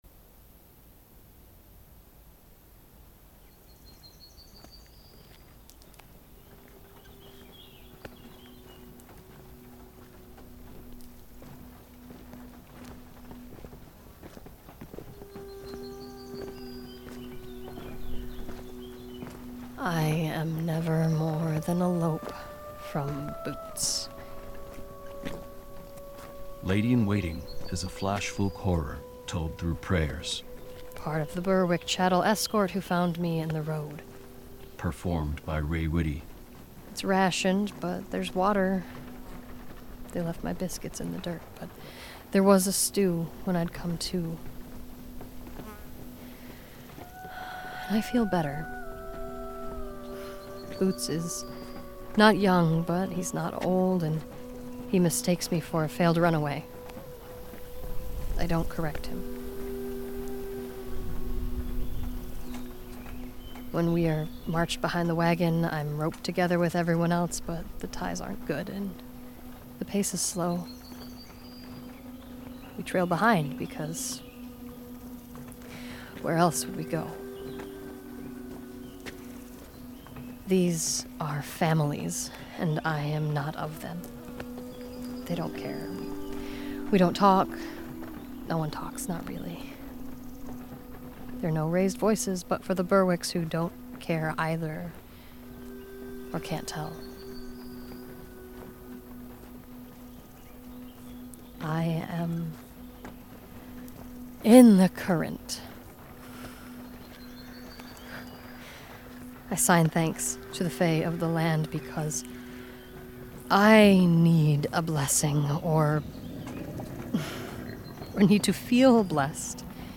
This is an immersive audio drama.